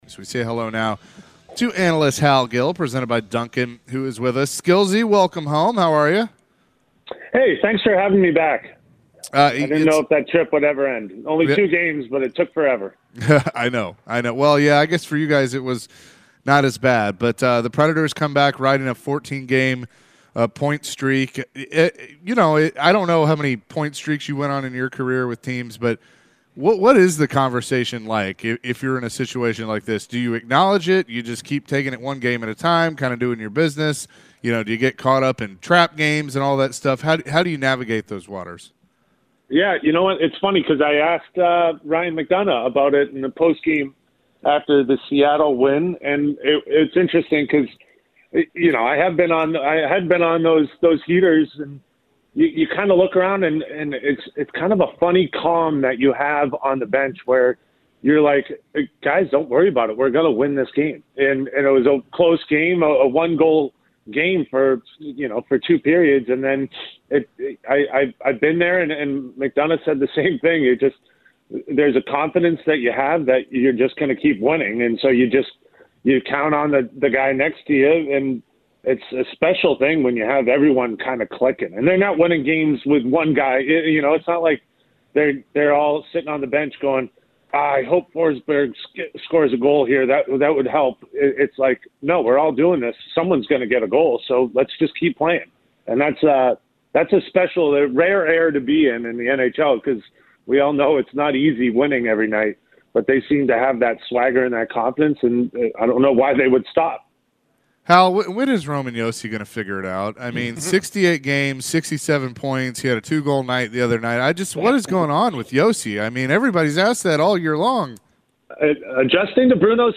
Hal Gill joined the show and shared his thoughts about the Preds recent form. Hal was also asked about the Preds current point streak.